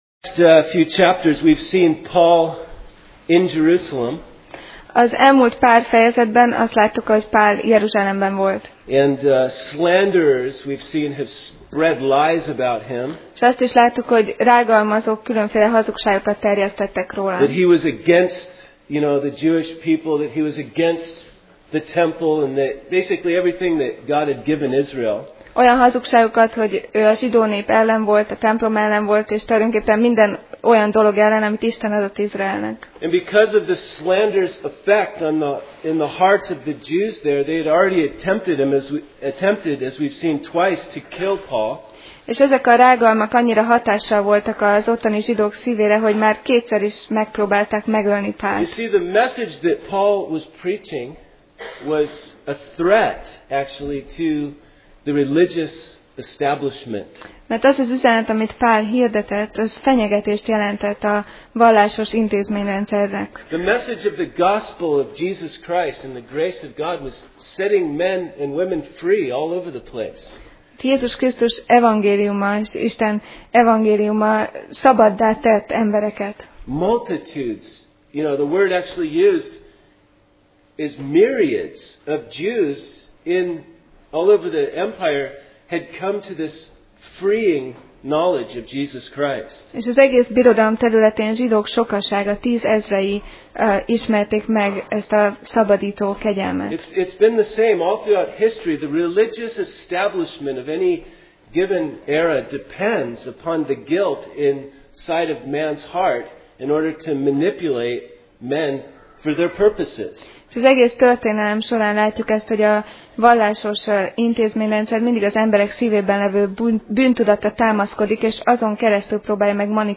Sorozat: Apostolok cselekedetei Passage: Apcsel (Acts) 22:30-23:24 Alkalom: Vasárnap Reggel